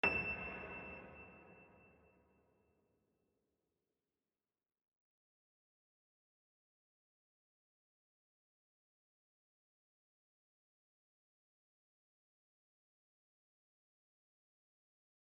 piano-sounds-dev
GreatAndSoftPiano
e6.mp3